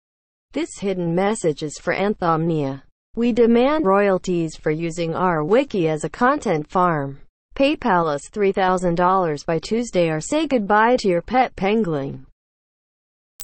Sfx_creature_shadowleviathan_roar_detect_01.ogg